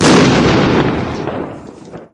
explosion5.mp3